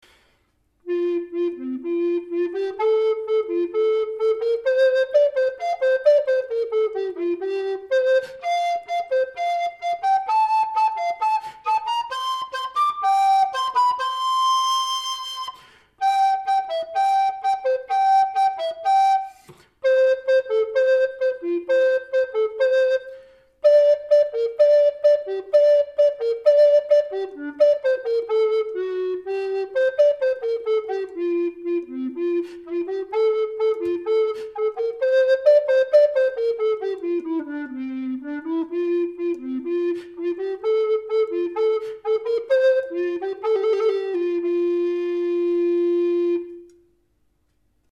Kung 1503 Studio Tenor Cherrywood Demo - Saunders Recorders
SAUNDERS RECORDERS AUDIO Kung Studio Tenor, 1503 Cherry wood.